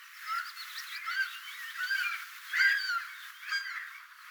nuoria kalalokkilintuja kaksi.
tällä tavon äänteli toinen linnuista
Selkeästi erilaista kuin täällä pesivillä kalalokeilla.
toinen_videolla_nakyvista_ilm_itaisista_nuorista_kalalokkilinnuista_aantelee.mp3